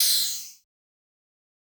OHAT - JAKE.wav